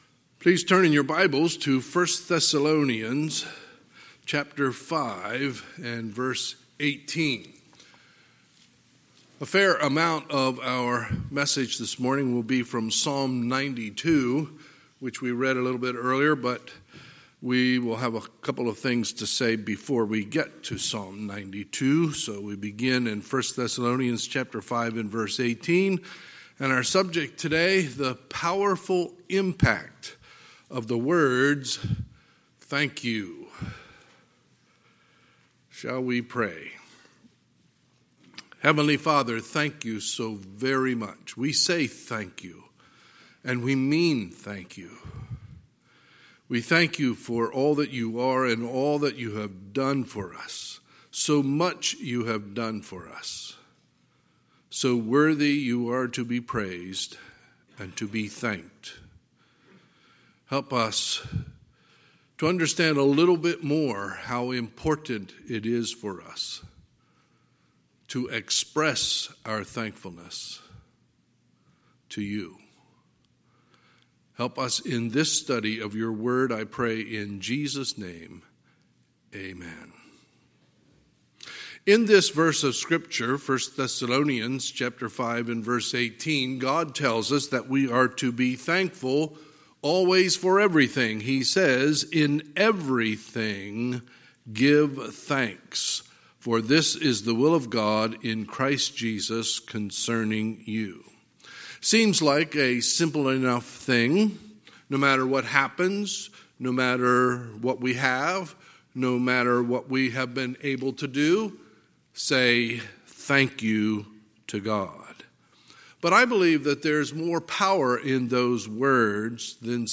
Sunday, November 22, 2020 – Sunday Morning Service